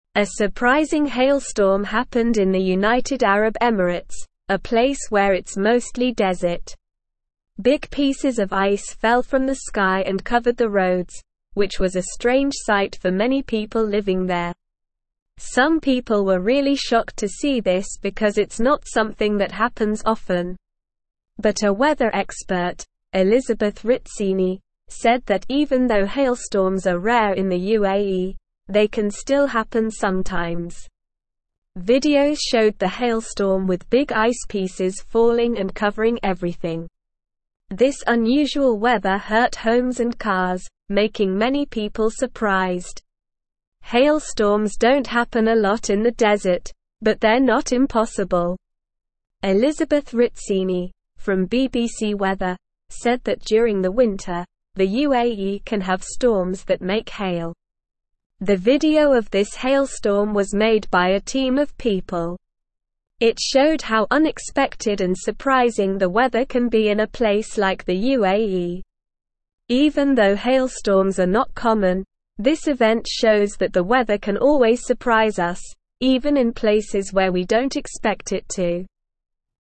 Slow
English-Newsroom-Lower-Intermediate-SLOW-Reading-Ice-Storm-in-United-Arab-Emirates-A-Surprising-Weather-Event.mp3